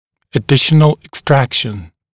[ə’dɪʃənl ɪk’strækʃn] [ek-][э’дишэнл ик’стрэкшн] [эк-]дополнительное удаление